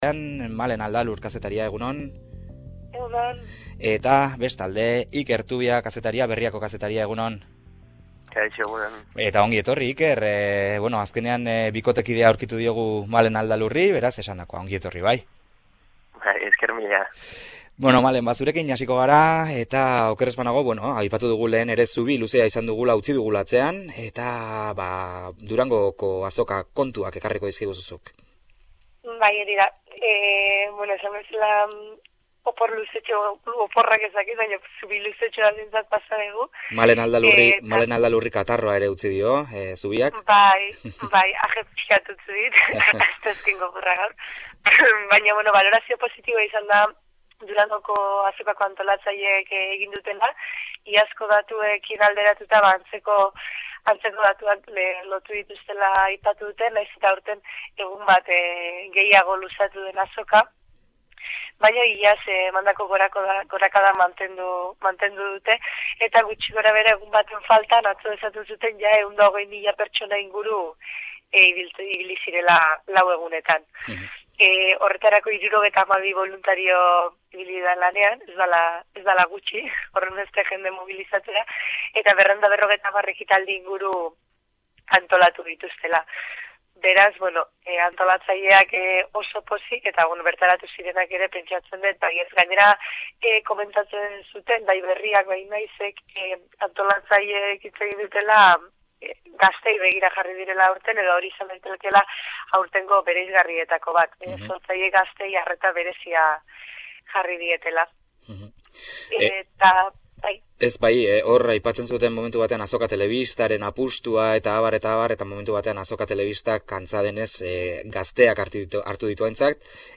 Hizpidea magazinearen hasieran bi kolaboratzaile izaten ditugu